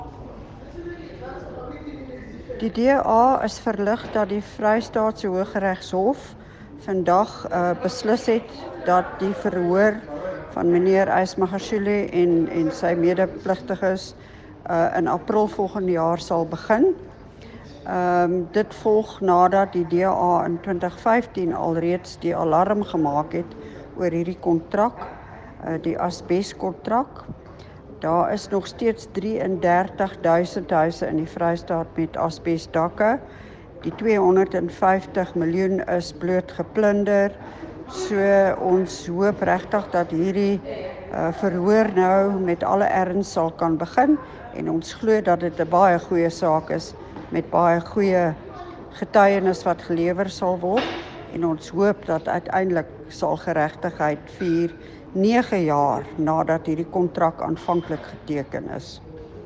Afrikaans soundbites by Leona Kleynhans MPL and